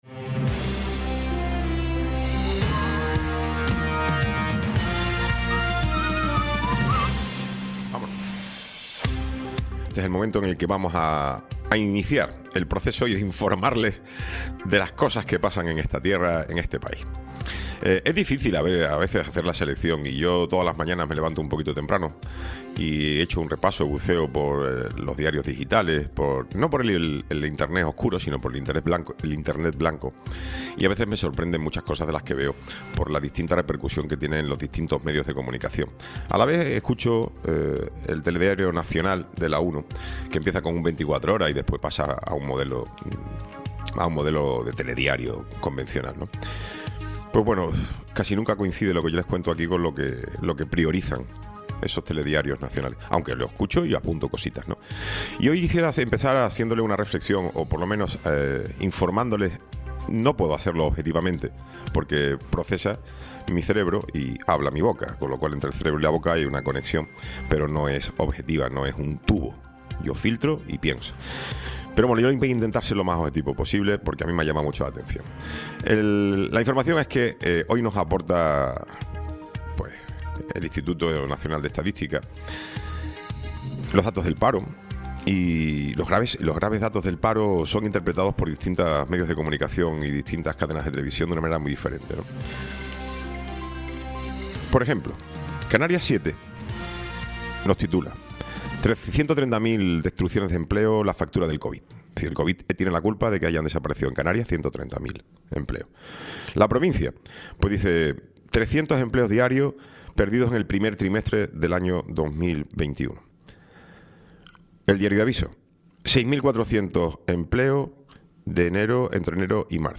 Felipe David Benítez. Concejal de Desarrollo Económico del Ayto. de La Orotava Tertulia